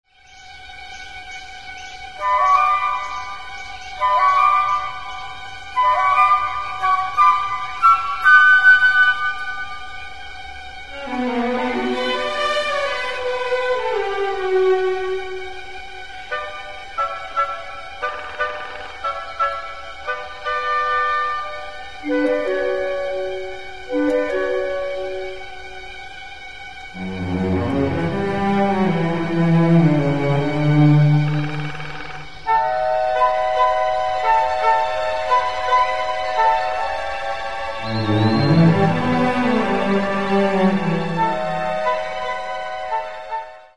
Каталог -> Другое -> Relax-piano, музыкальная терапия
звуки птиц